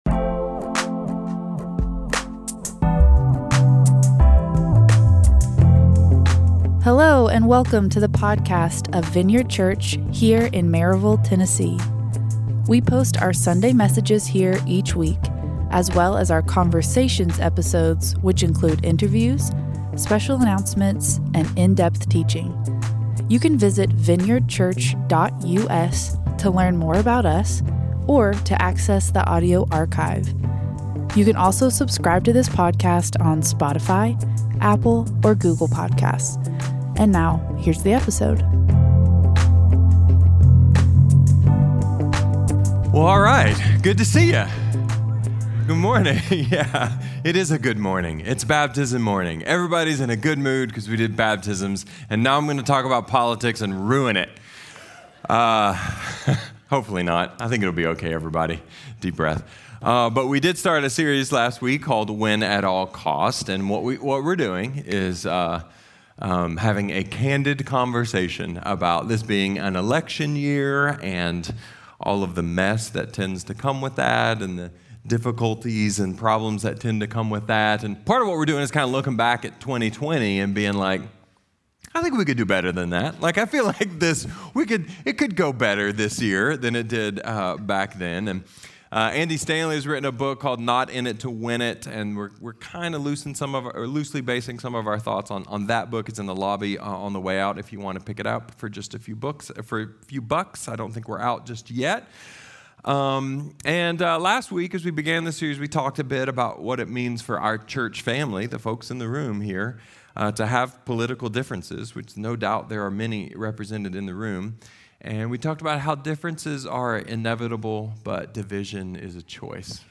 A message from the series "Win At All Costs."